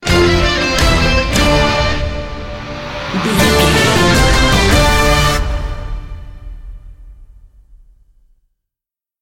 Ionian/Major
D
horns
drums
electric guitar
synthesiser
orchestral
orchestral hybrid
dubstep
aggressive
energetic
intense
strings
bass
synth effects
wobbles
heroic
driving drum beat
epic